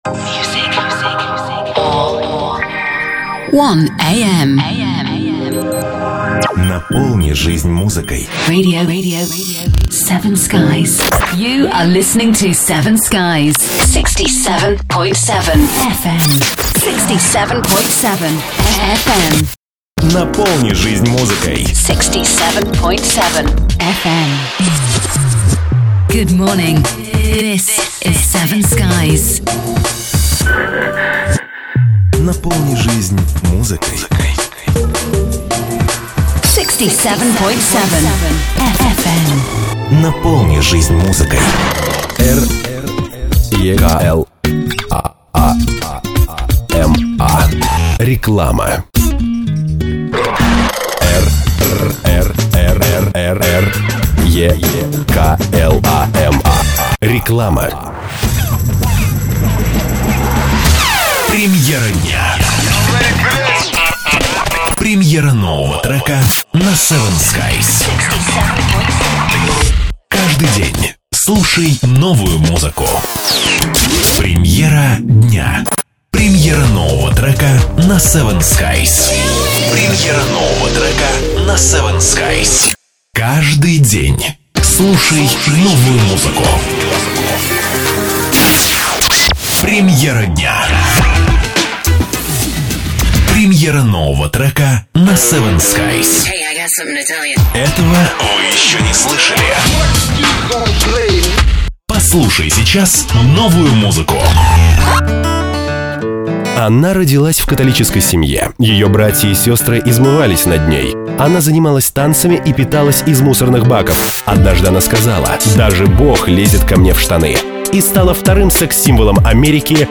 Оформление радио эфира.
Оформление эфира.